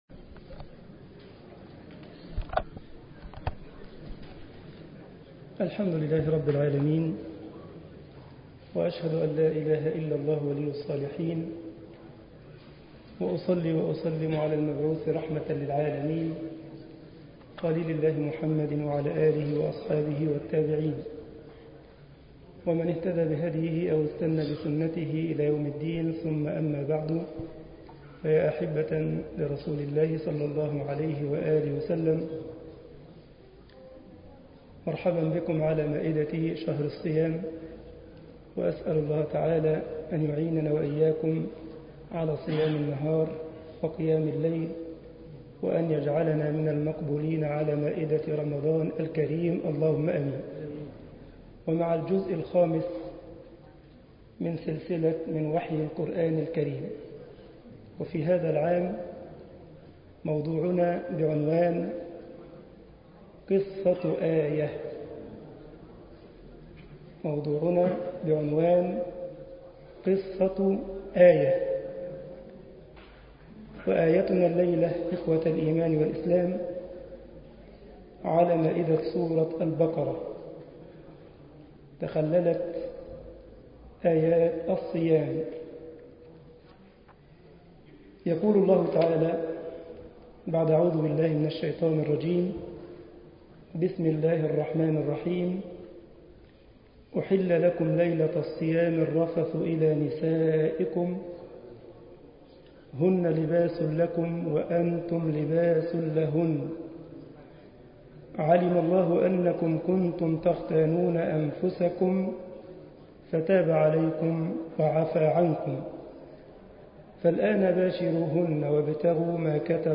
مسجد الجمعية الإسلامية بالسارلند ـ ألمانيا
درس 01 رمضان 1432 هـ الموافق 01 أغسطس 2011 م